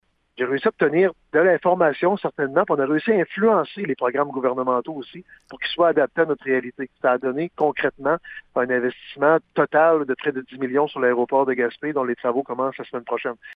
Enfin, Daniel Côté affirme que son rôle au sein de l’UMQ va apporter une grande visibilité à Gaspé et a déjà donné des résultats pour sa Ville: